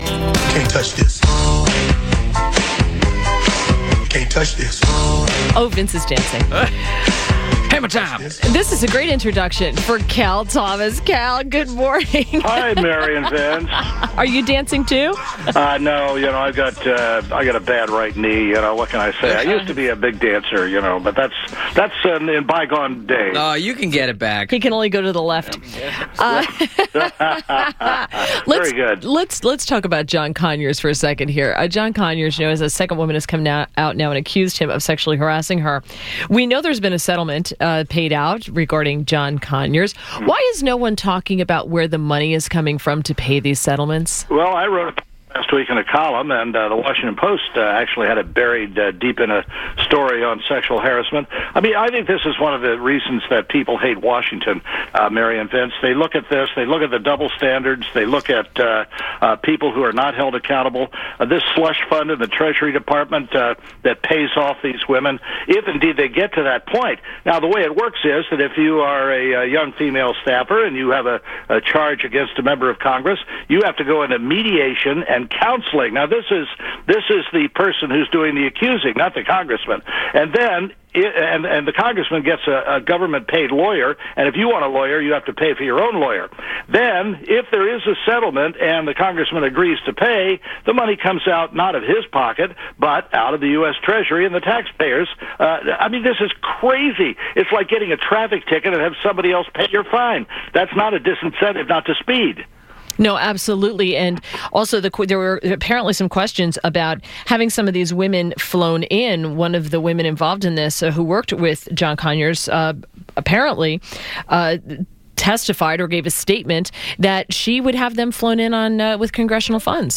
INTERVIEW - CAL THOMAS - Syndicated columnist